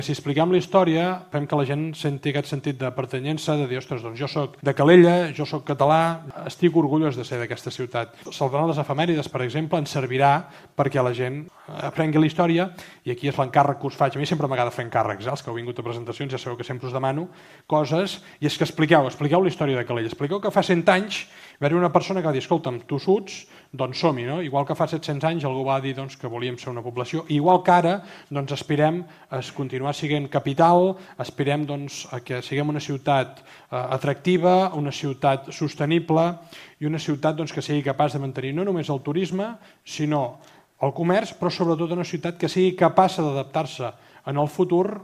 En el seu parlament per cloure l’acte, l’alcalde Marc Buch va encoratjar els assistents i la ciutadania en general a interessar-se per la història de Calella i compartir-la, per així estendre i reforçar el sentiment de pertinença i l’orgull de formar part de la comunitat local.